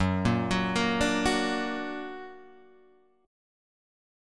F# Augmented